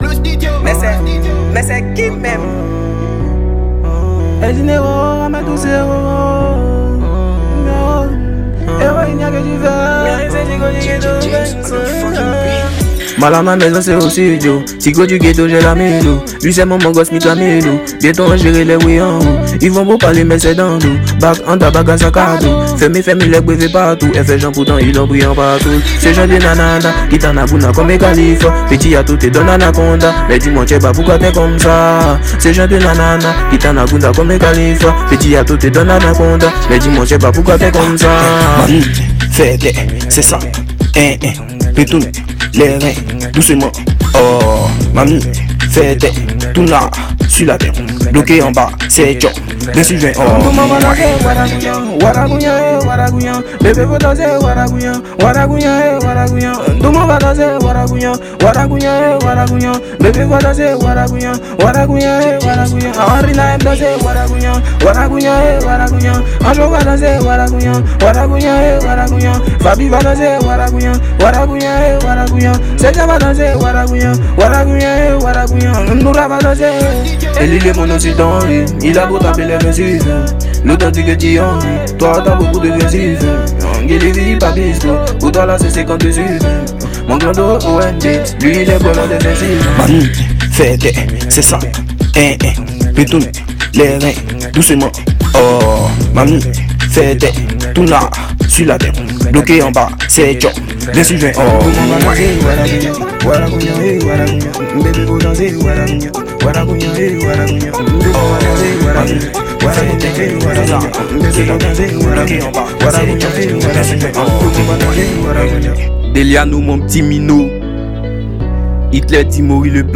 | Rap ivoire